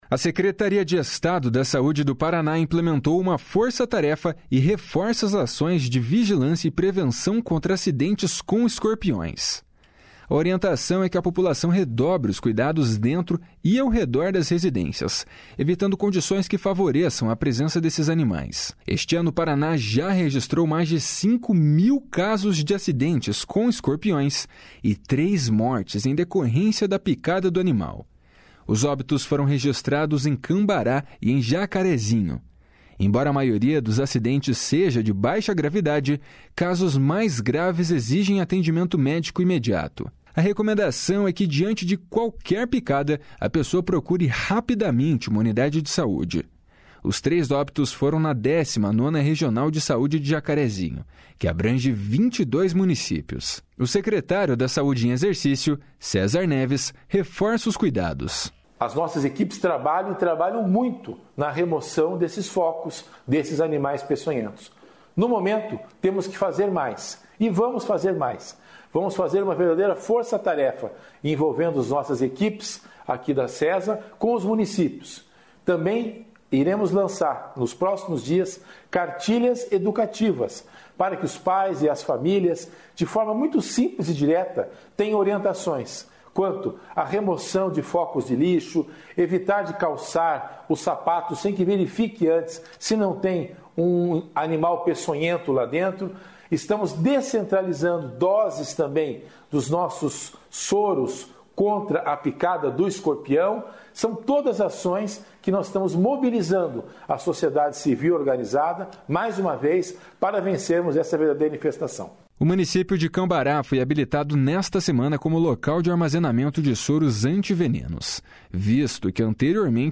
O secretário da Saúde em exercício, César Neves, reforça os cuidados. // SONORA CÉSAR NEVES //